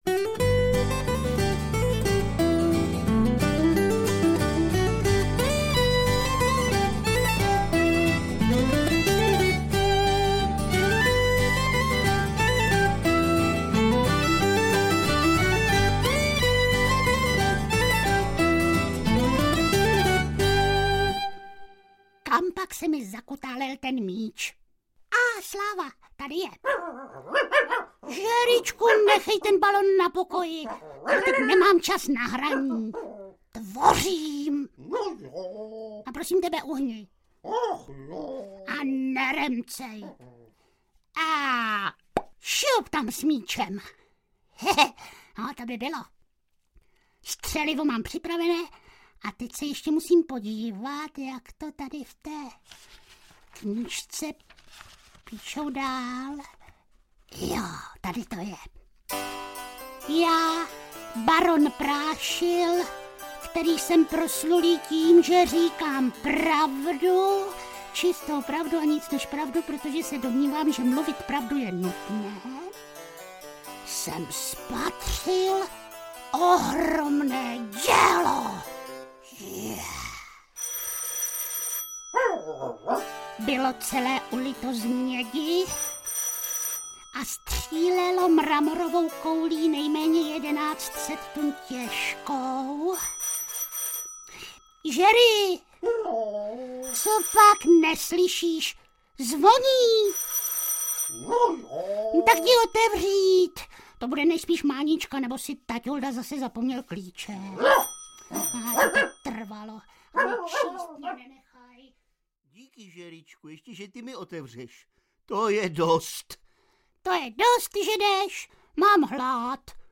Audiokniha
Zvukově nadobyčej barevná "deska" nabízí malým dobrodružství a velkým úsměvnou zábavu.